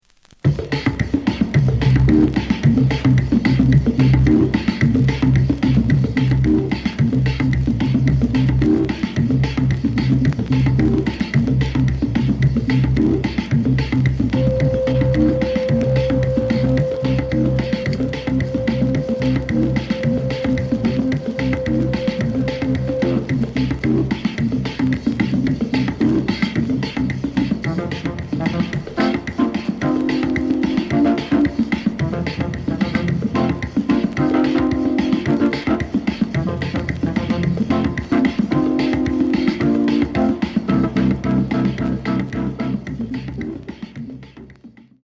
The listen sample is recorded from the actual item.
●Format: 7 inch
●Genre: Soul, 70's Soul